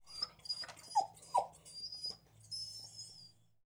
Whimper2.wav